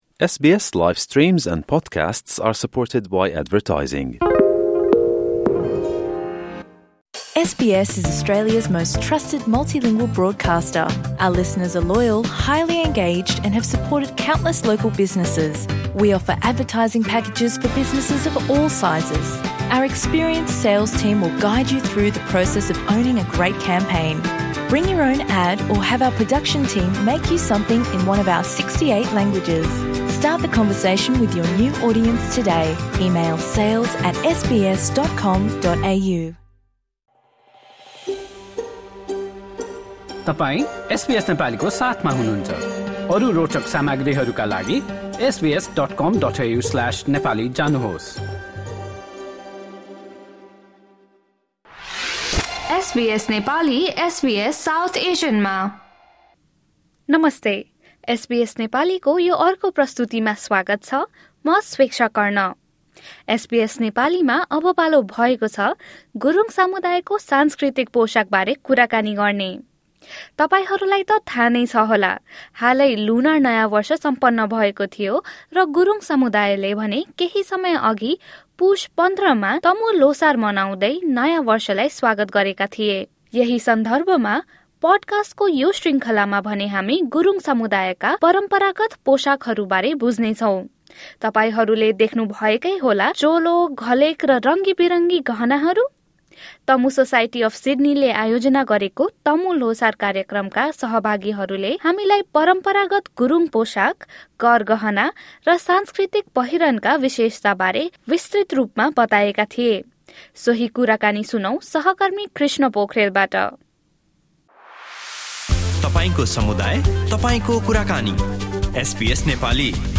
हालै लुनार नयाँ वर्ष सम्पन्न भएको थियो र त्यसैसँगै गुरुङ समुदायले भने पुष १५ मा तमु ल्होसार मनाउँदै नयाँ वर्षलाई स्वागत गरेका थिए। यसै क्रममा, तमु सोसाइटी अफ सिड्नीले आयोजना गरेको तमु ल्होसार कार्यक्रमका सहभागीहरूले आफ्ना परम्परागत गुरुङ पोसाक, गरगहना, र सांस्कृतिक पहिरनका विशेषताबारे एसबीएस नेपालीसँग गरेको कुराकानी सुन्नुहोस्।